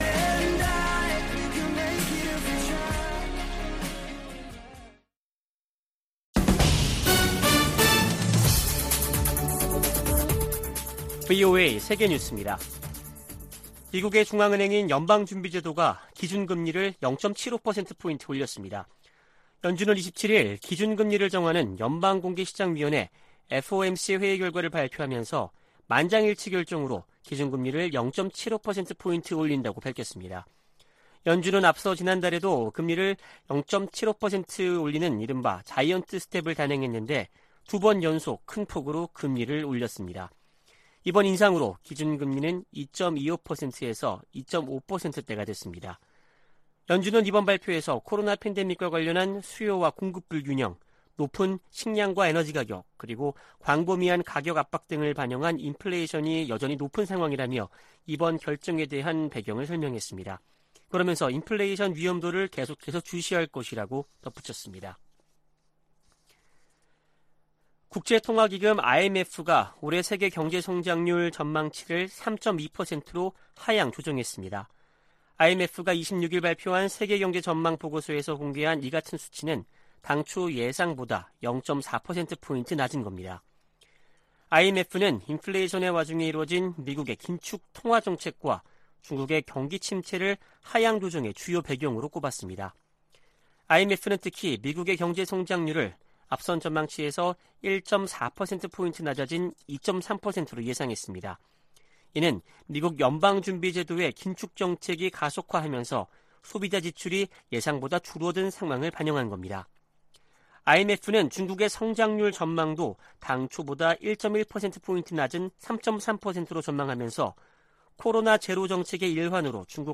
VOA 한국어 아침 뉴스 프로그램 '워싱턴 뉴스 광장' 2022년 7월 28일 방송입니다. 미국 정부가 북한의 추가 핵실험이 한반도의 불안정성을 가중시킬 것이라며 동맹과 적절히 대응할 것이라고 밝혔습니다. 박진 한국 외교부 장관이 북한이 7차 핵실험을 감행하면 더 강력한 국제사회 제재에 직면할 것이라고 경고했습니다.